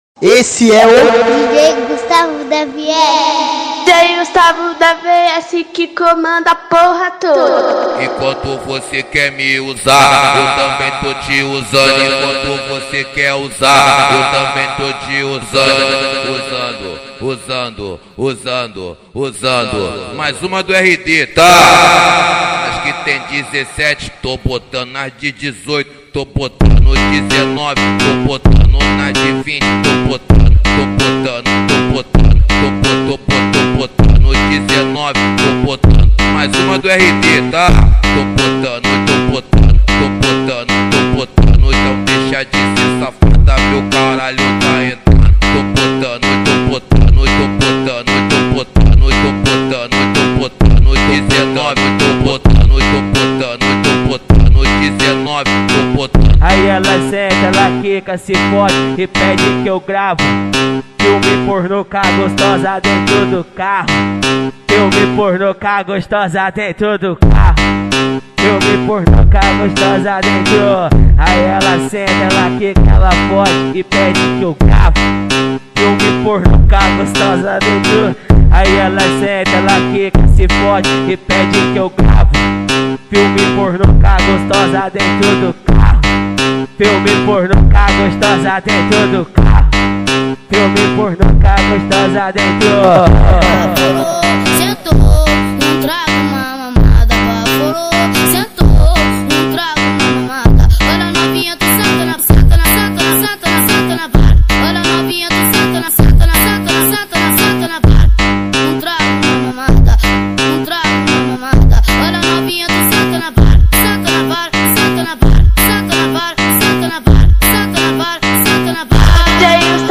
2024-06-23 08:52:41 Gênero: MPB Views